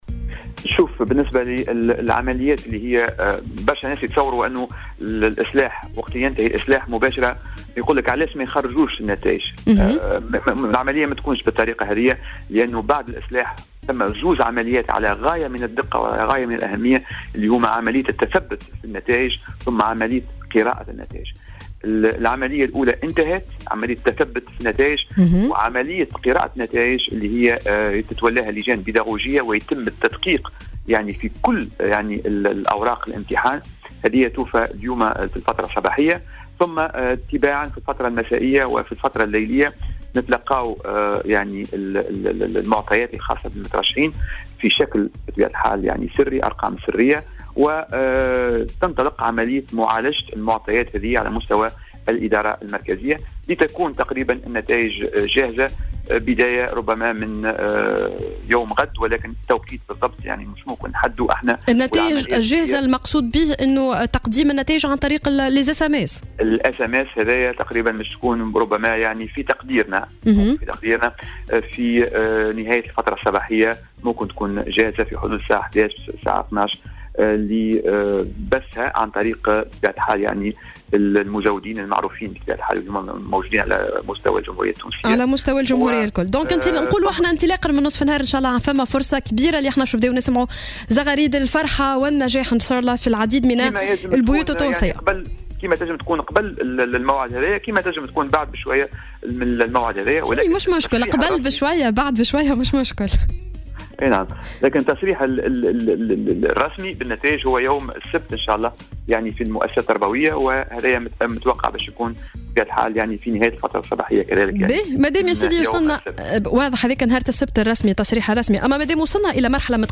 وقال في تصريح لـ "الجوهرة أف أم" ببرنامج "صباح الورد" إن النتائج جاهزة تقريبا حيث انتهت عملية الاصلاح وعملية التثبت في النتائج فيما يتم حاليا اعادة قراءتها والتدقيق فيها وستنتهي العملية في وقت لاحق من اليوم. وأضاف أن النتائج جاهزة ظهر الغد على أقصى تقدير عبر الإرساليات القصيرة فيما سيتم التصريح بها بشكل رسمي صباح السبت 18 جوان 2016.